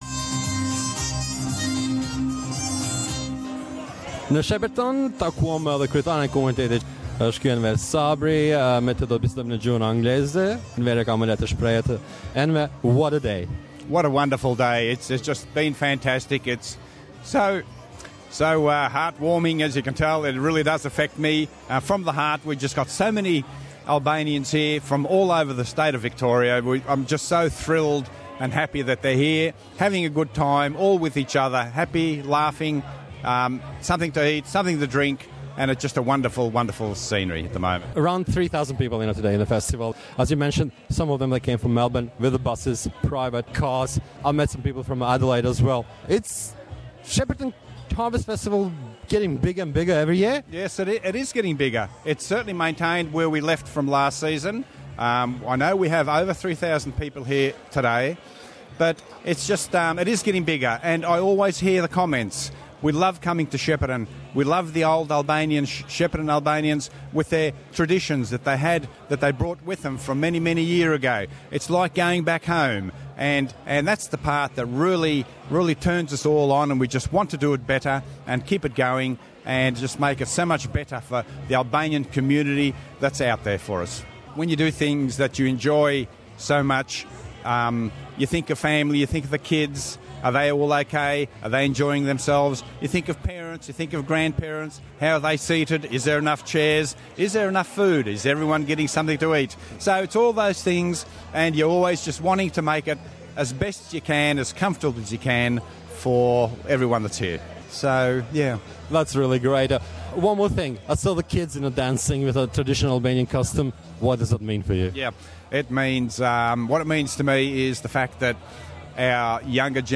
On March 18 in Shepparton was held the Albanian Harvest Festival, which was one of the most visited festivals so far. Thousands of people came from all over Australia to be part of an Albanian-Australian tradition.